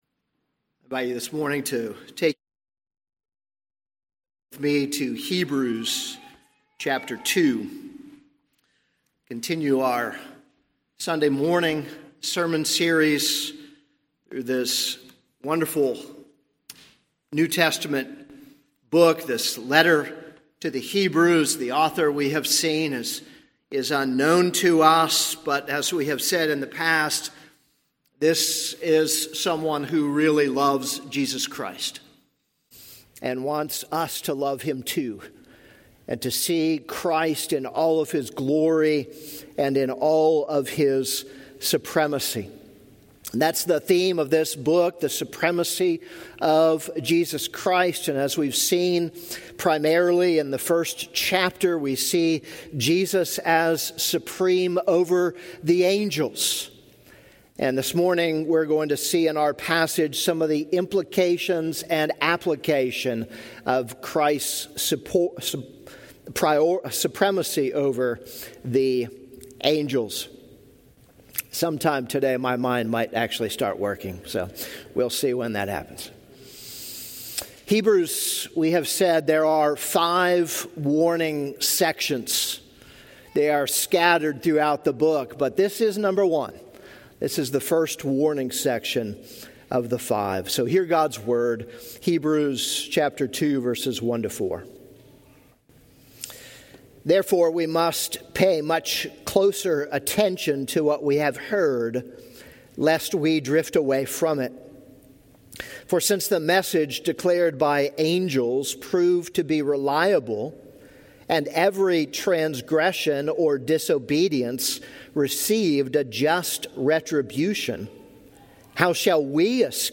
This is a sermon on Hebrews 2:1-4.